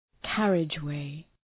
Προφορά
{‘kærıdʒ,weı}